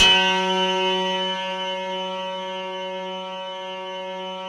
RESMET F#3-L.wav